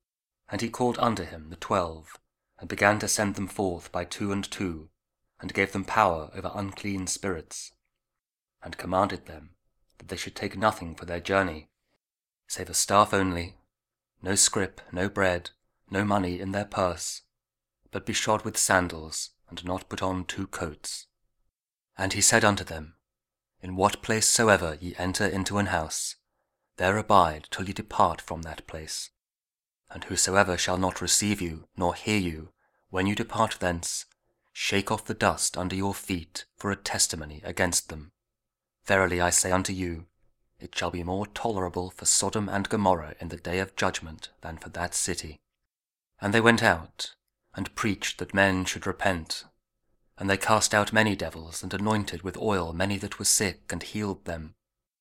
Mark 6: 7-13 – Week 4 Ordinary Time, Thursday (King James Audio Bible KJV, Spoken Word)